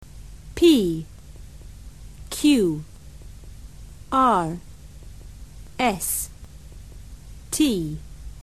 Escucha cómo pronuncia la letra Z en el Reino Unido y en los Estados Unidos.